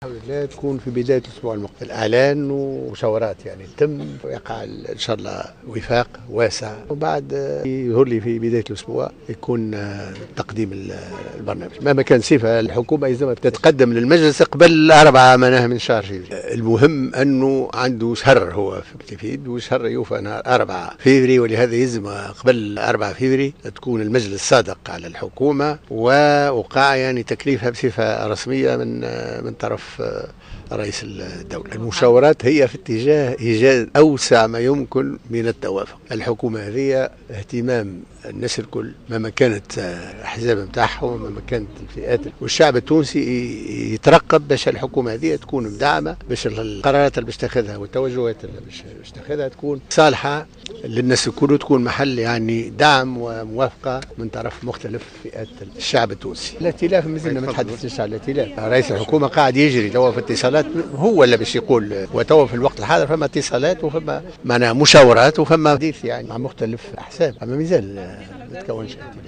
Le président de l’Assemblée des représentants du peuple, Mohamed Ennaceur, a mis l’accent, mardi au micro de Jawhara Fm sur l’impératif de présenter la composition définitive du nouveau gouvernement et de son programme dans les plus brefs délais.